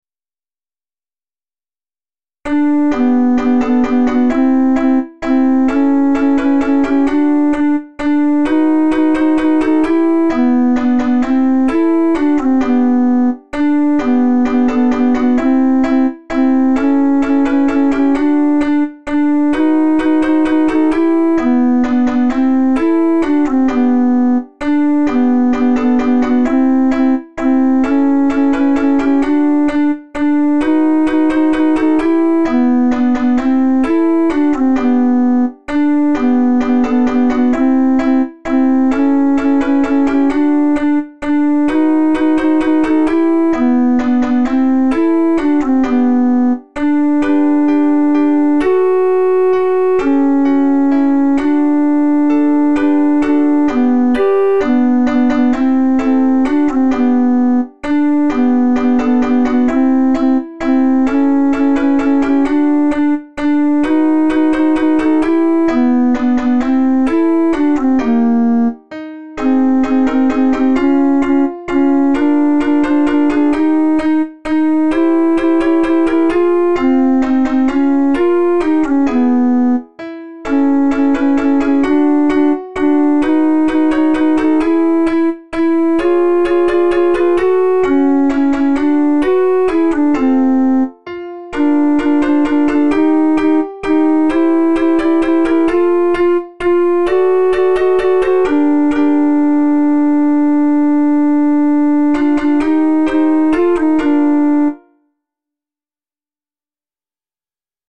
We-Wish-You-A-Merry-Christmas-BOT.mp3